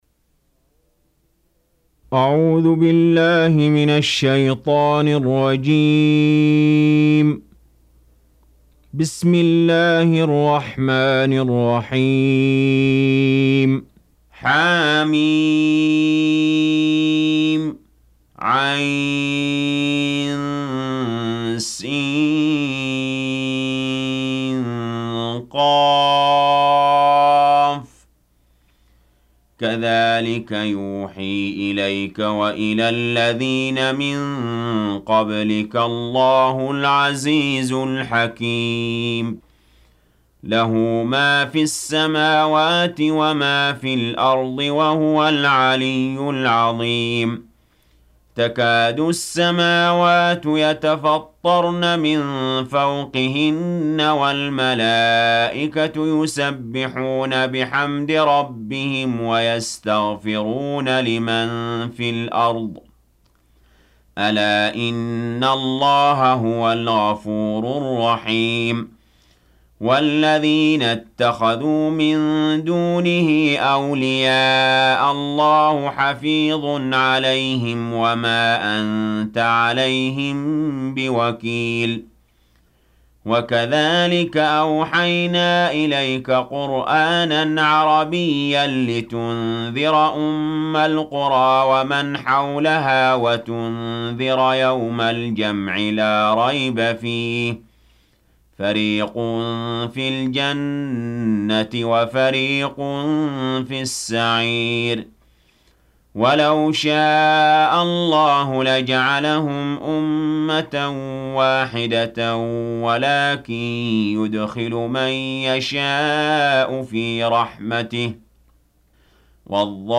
42. Surah Ash-Sh�ra سورة الشورى Audio Quran Tarteel Recitation
Surah Sequence تتابع السورة Download Surah حمّل السورة Reciting Murattalah Audio for 42. Surah Ash-Sh�ra سورة الشورى N.B *Surah Includes Al-Basmalah Reciters Sequents تتابع التلاوات Reciters Repeats تكرار التلاوات